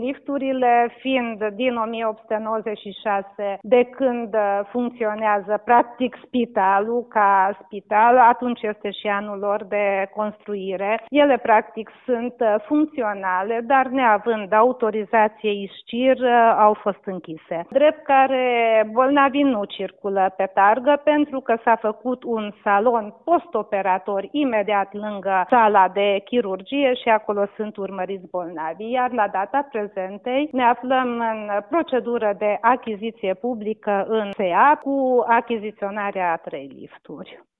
a acordat astăzi un interviu pentru Radio Tg. Mureş